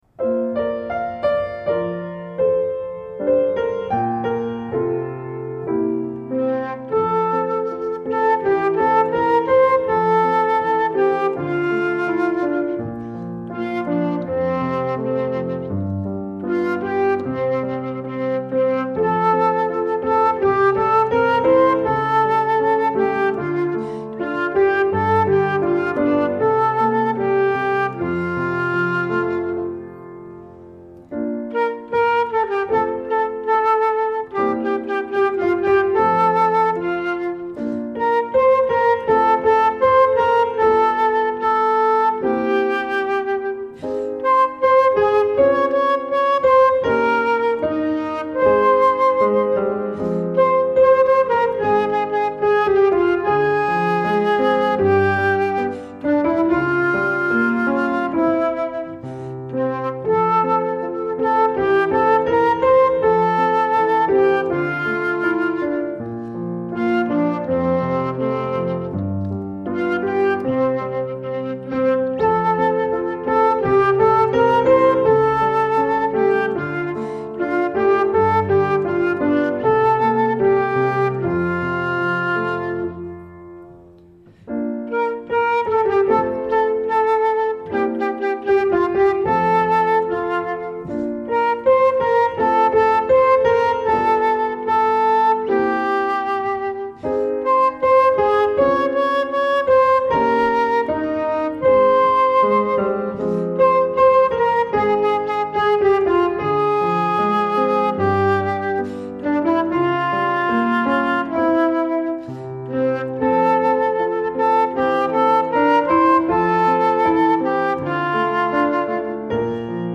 Audio voix 2